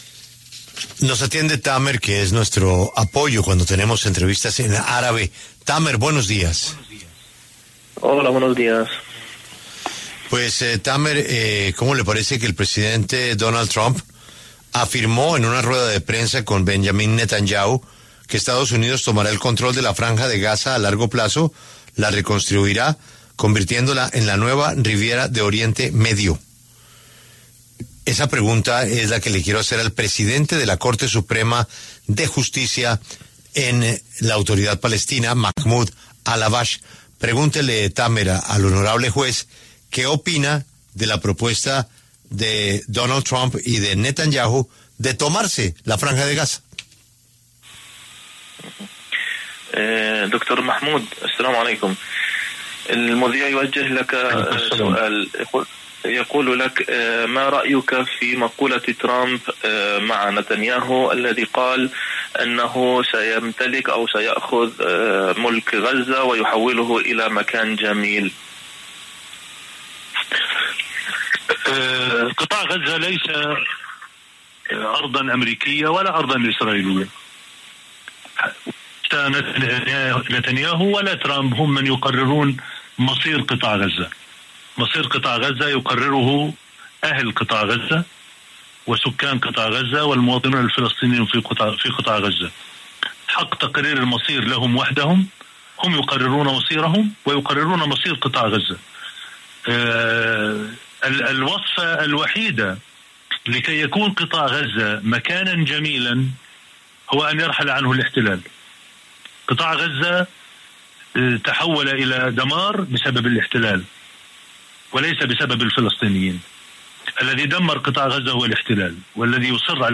Mahmoud Al-Habbash, presidente del Tribunal Supremo de Palestina y asesor del presidente de la Autoridad Palestina Mahmoud Abbas, pasó por los micrófonos de La W, con Julio Sánchez Cristo, para hablar de las palabras de Donald Trump de ocupar ese territorio para reconstruirlo.